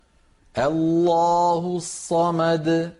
c ـــ Den uttalas tungt (tafkhim) om den kommer i början som i: